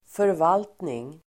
Uttal: [förv'al:tning]